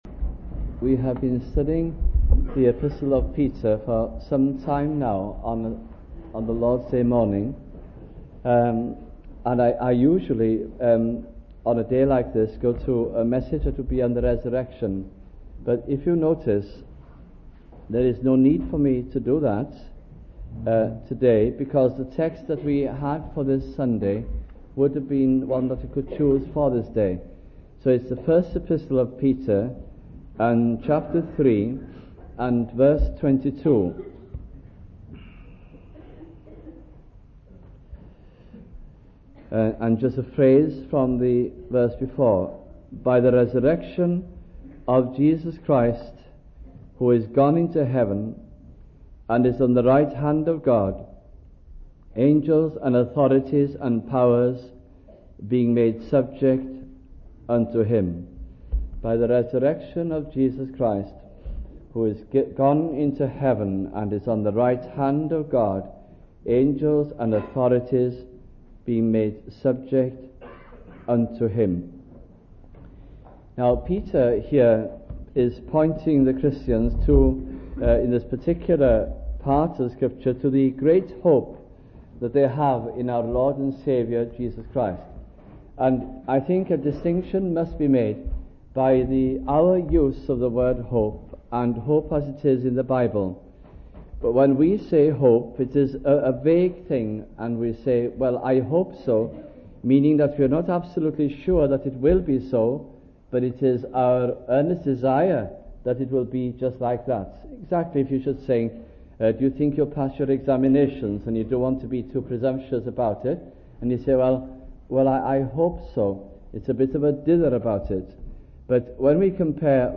» First Epistle of Peter Series 1982 - 1983 » sunday morning messages from this gracious epistle